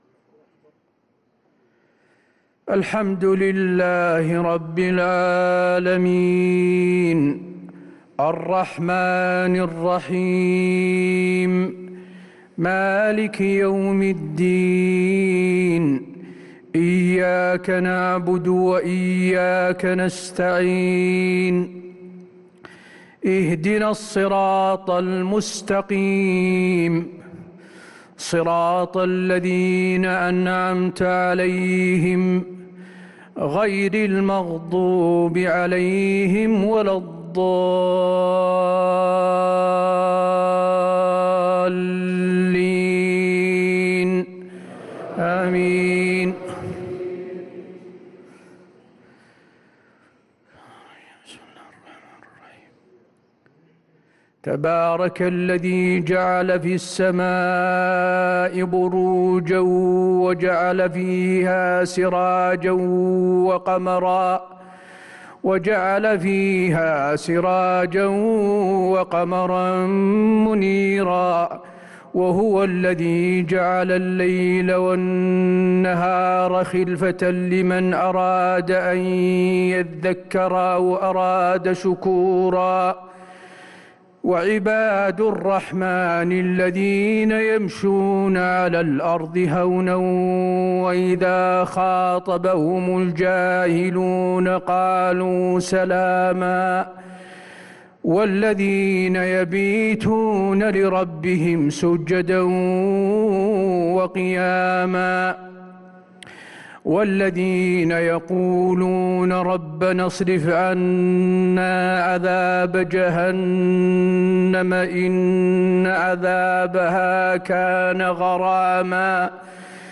عشاء الاثنين 9-2-1444هـ خواتيم سورة الفرقان61-77 | lsha prayer from Surah Al-Furqan 5-9-2022 > 1444 🕌 > الفروض - تلاوات الحرمين